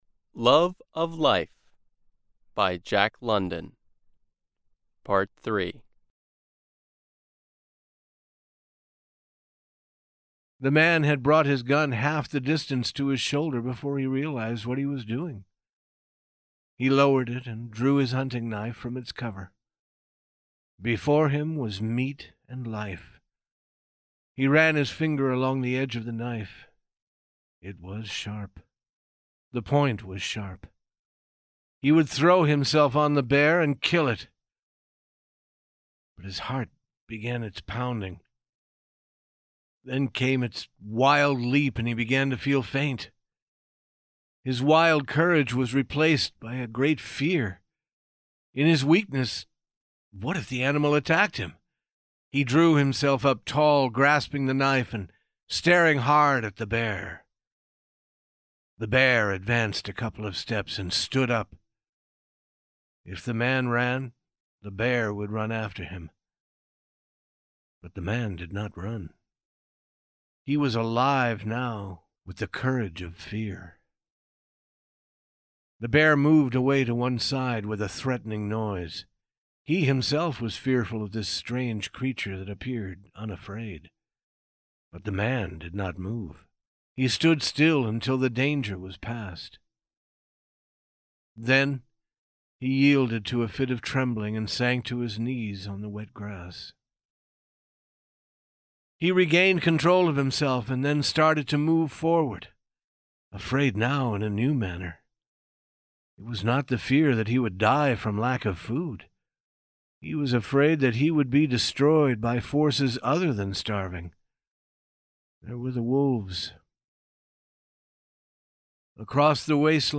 We present the third of four parts of the short story "Love of Life," by Jack London. The story was originally adapted and recorded by the U.S. Department of State.